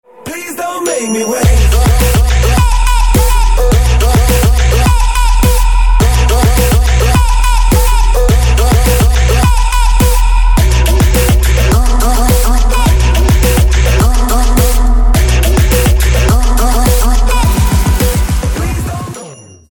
• Качество: 320, Stereo
dance
Electronic
Trap
club
electro